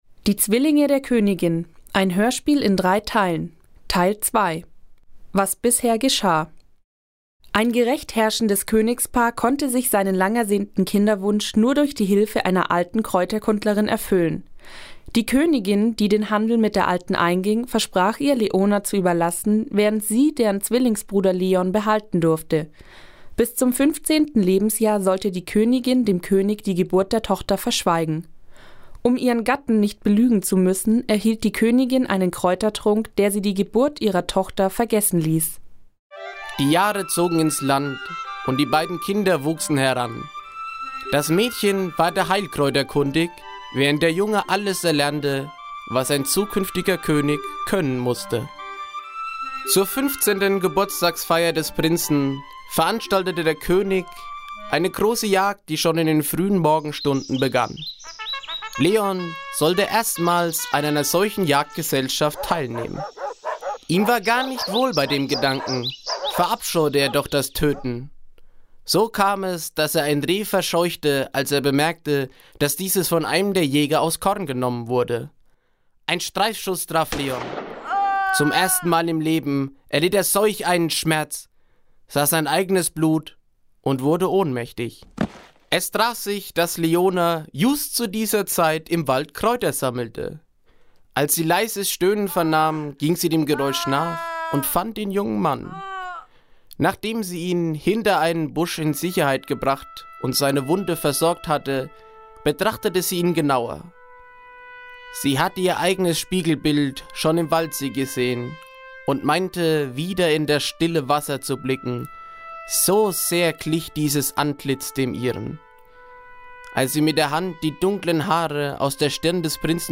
12. Hörspiel